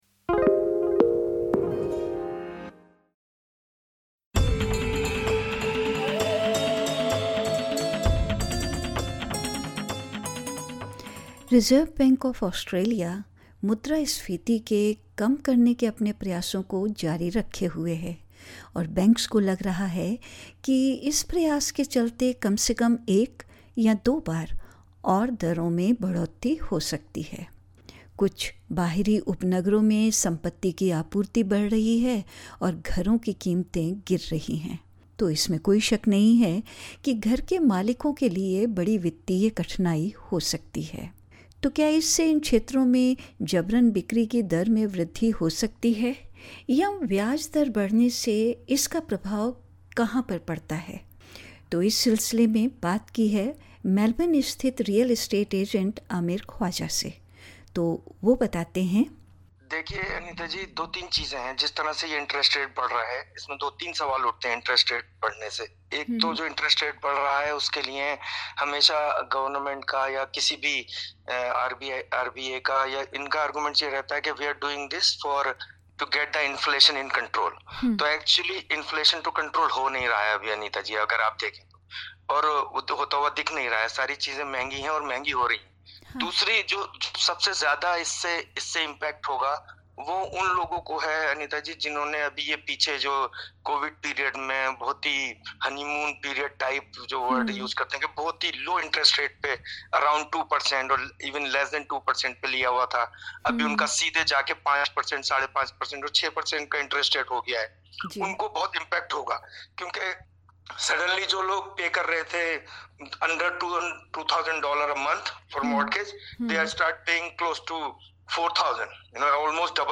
अस्वीकरण: हम आपको सूचित करना चाहेंगे कि इस साक्षात्कार में व्यक्त की गई जानकारी सामान्य प्रकृति की है और साक्षात्कारकर्ता के निजी विचार हैं।